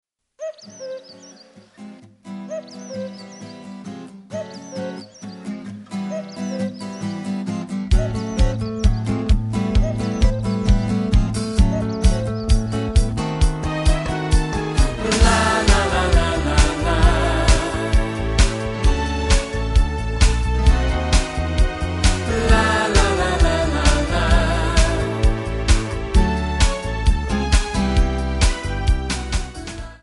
Backing track files: Rock (2136)
Buy With Backing Vocals.